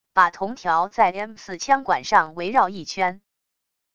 把铜条在M4枪管上围绕一圈wav音频